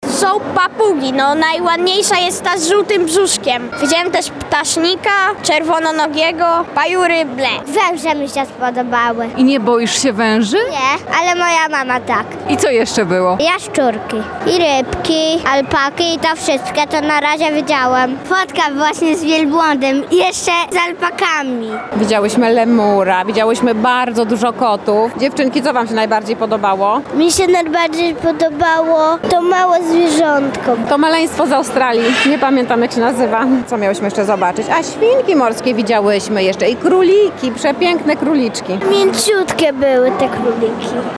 Wszystkie są fajne – mówią odwiedzający targi.